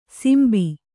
♪ simbi